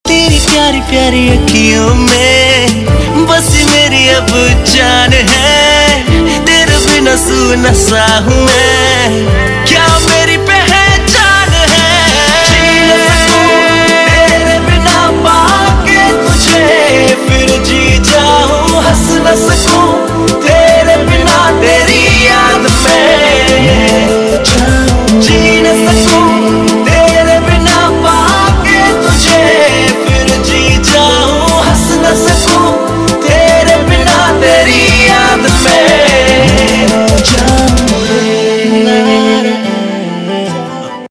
Indian POP